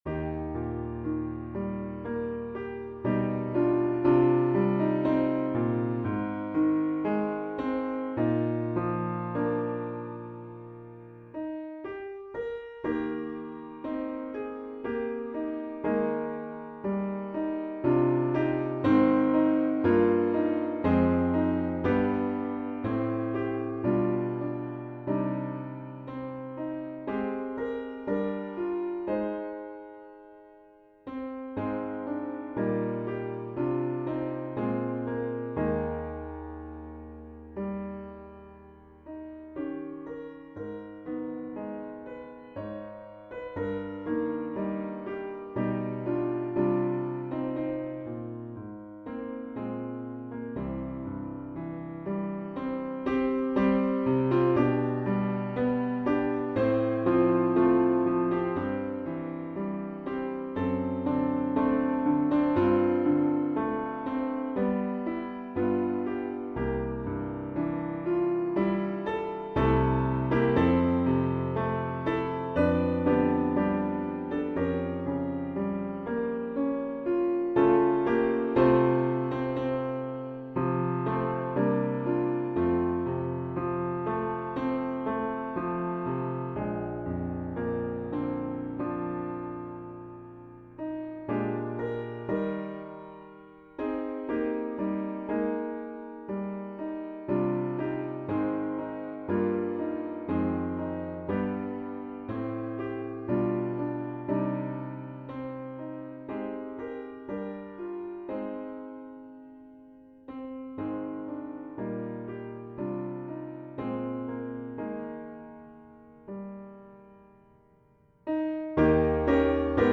The mp3 is a midi recording.